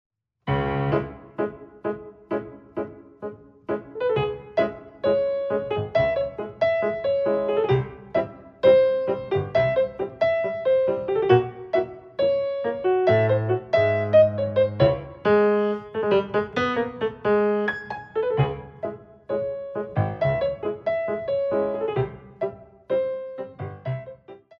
Tango
Ballet Class Music For First Years of Ballet